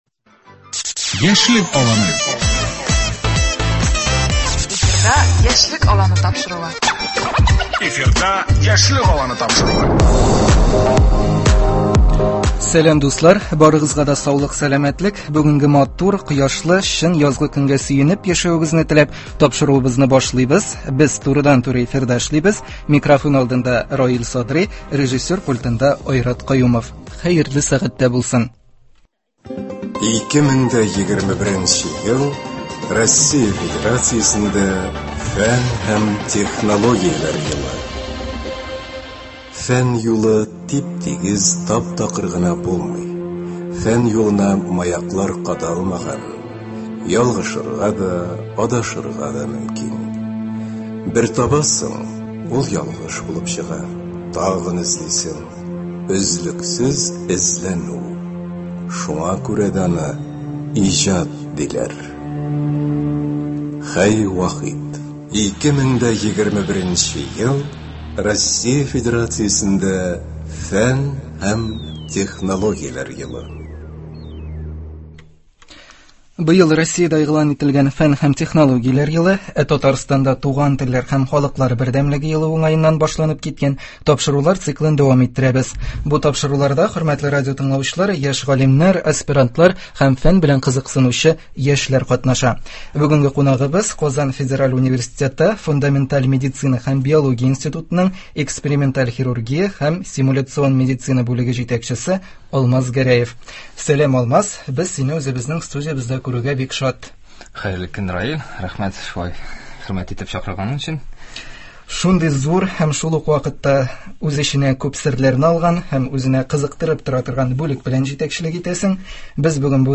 Бу тапшыруларда, хөрмәтле радиотыңлаучылар, яшь галимнәр, аспирантлар һәм фән белән кызыксынучы яшьләр катнаша.